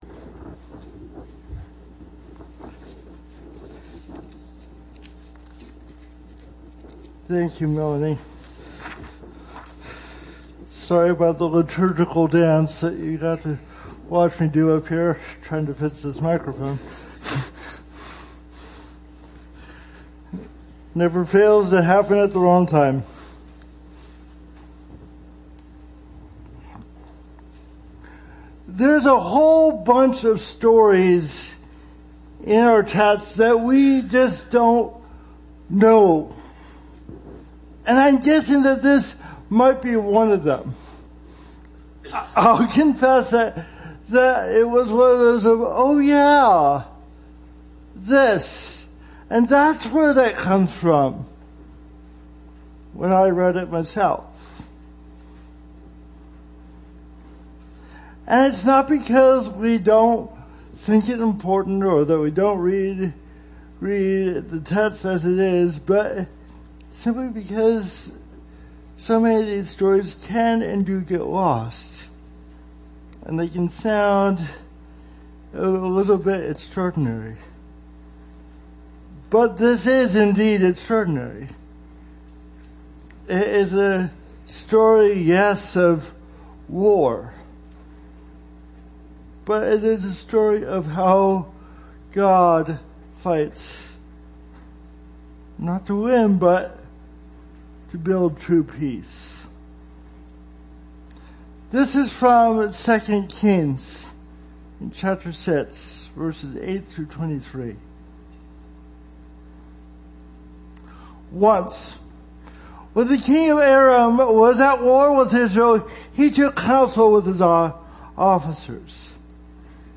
Sermons Archive - Page 3 of 39 - Menno Mennonite Church
Service Type: Sunday Morning